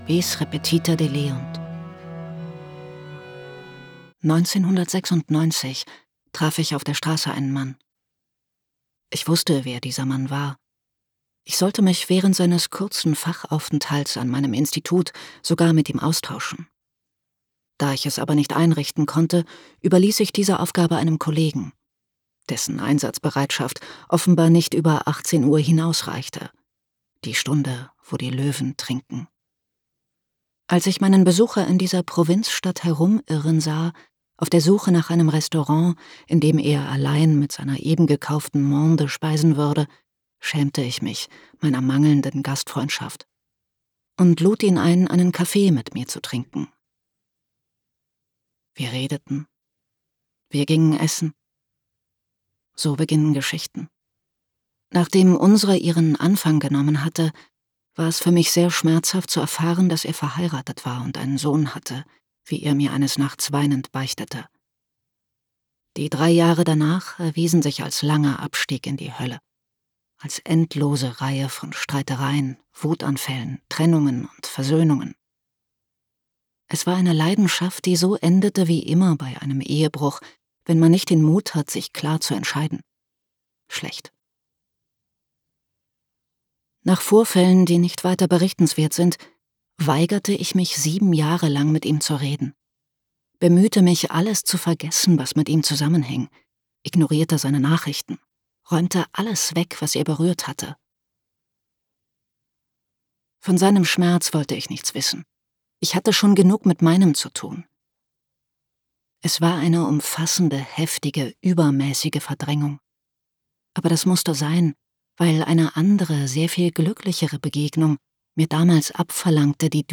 Henry Purcell (Komponist)